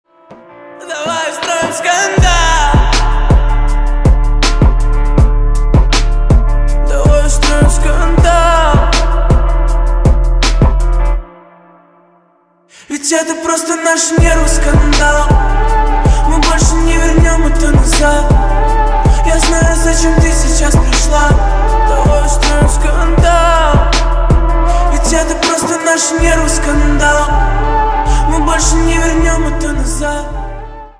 поп
спокойные
чувственные
нежные